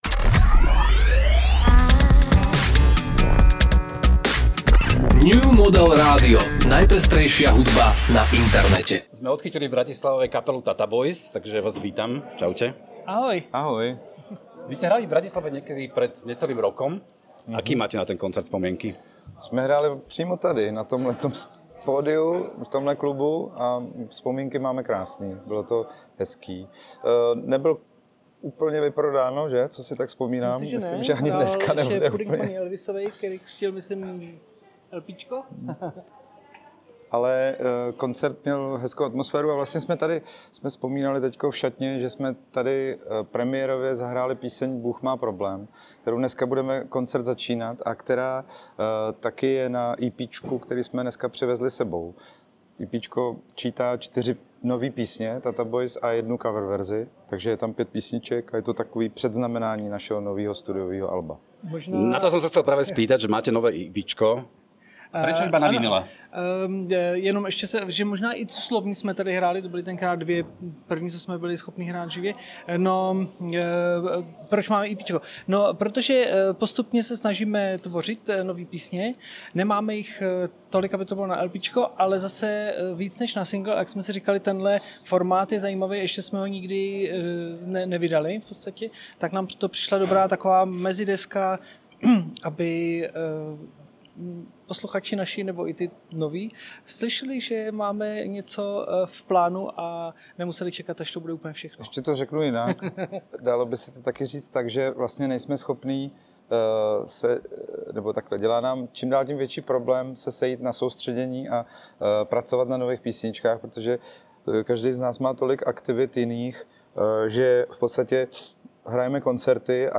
Mardušu a Milana Caisa z Tata Bojs sme vyspovedali pred ich bratislavským koncertom 11.4.2025.